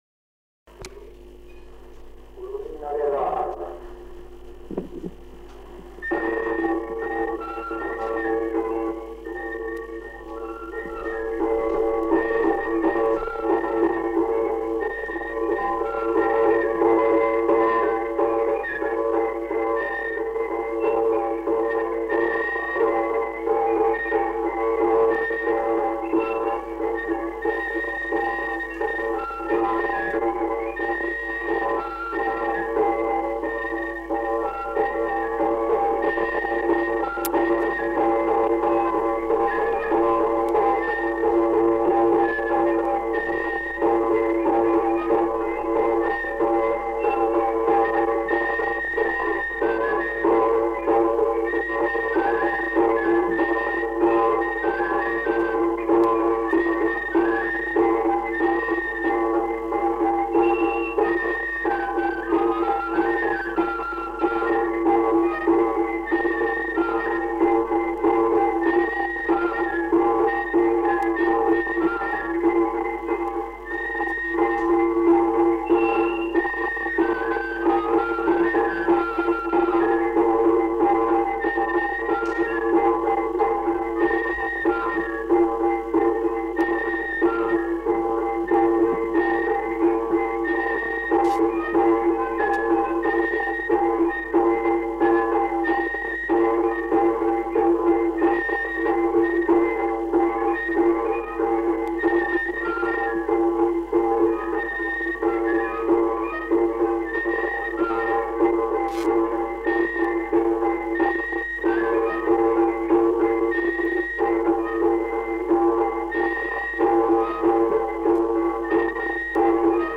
Aire culturelle : Béarn
Lieu : Bielle
Genre : morceau instrumental
Instrument de musique : flûte à trois trous ; tambourin à cordes
Danse : branlo airejan